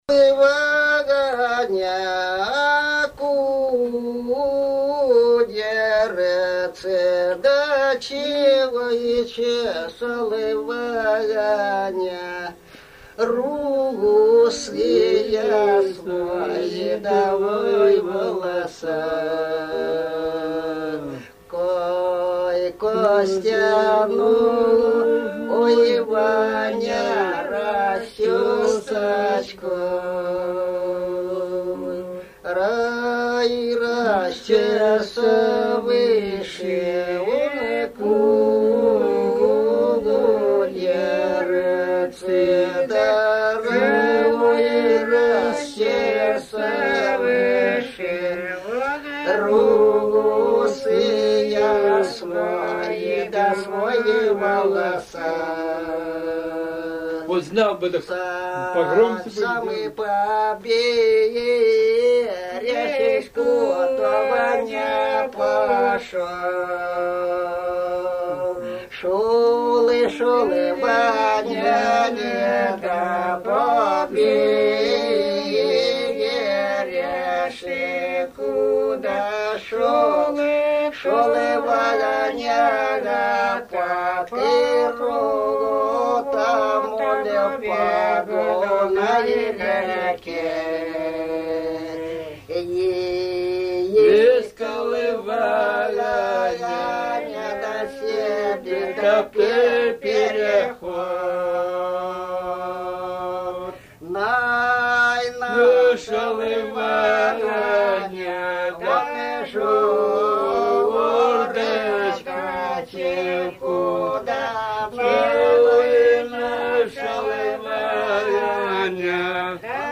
Старинные песни
"Чесал Ваня кудерцы" протяжная
с. Кежма, 1993г.